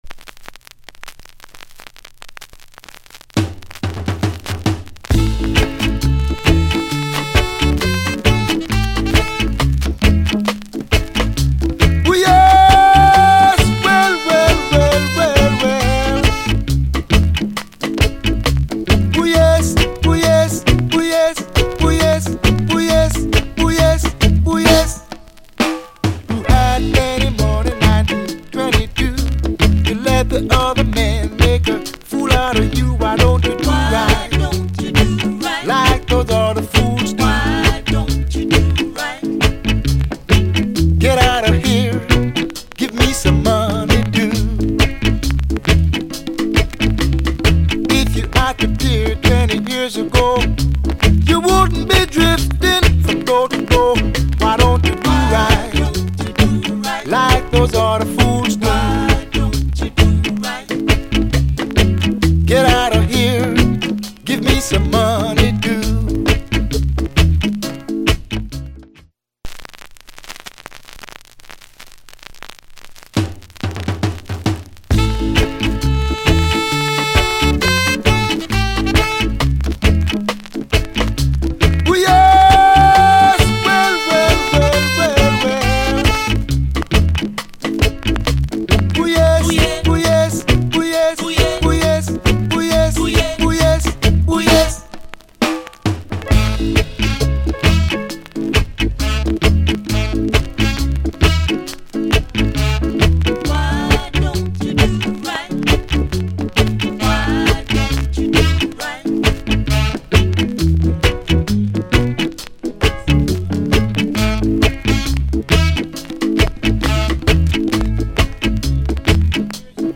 * ミッド・テンポ、熱いボーカル、ブレイクありのあまりない感じ。